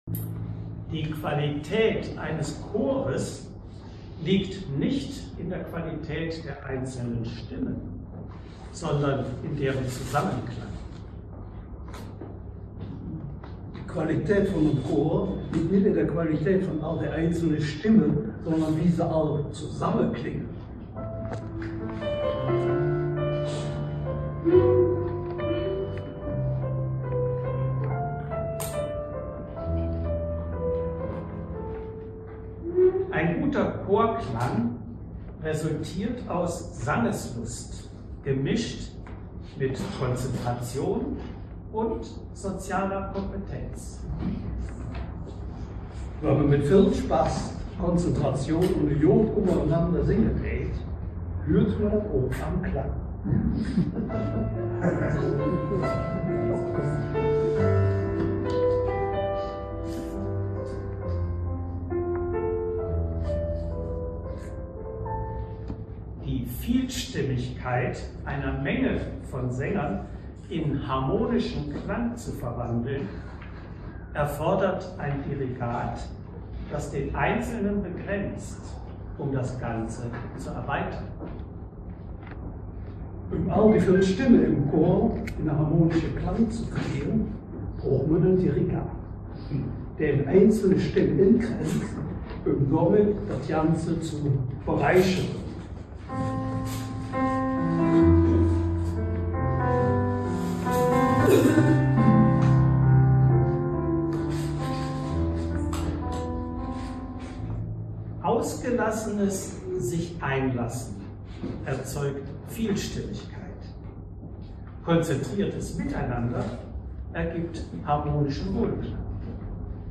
Es handelt sich um eine Live-Aufnahme anlässlich einer Ganztags-Chorprobe vom 19.10.2024.